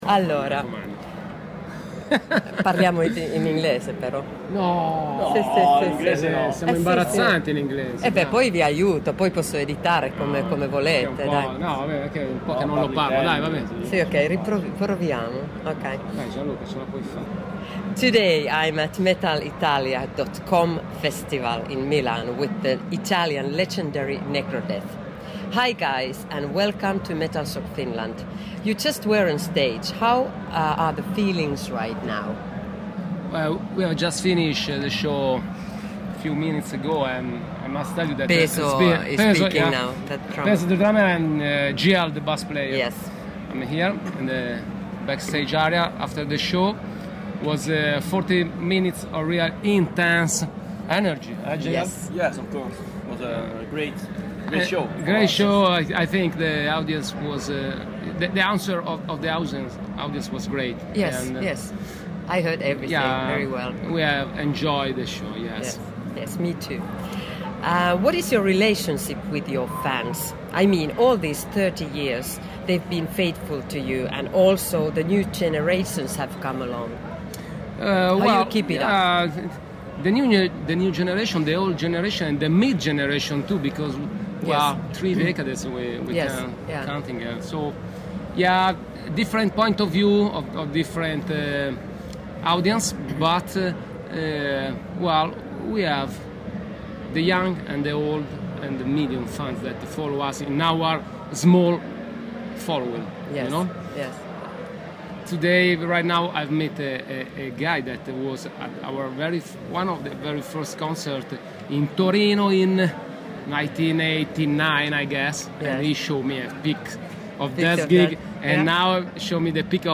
Interview
During the chat backstage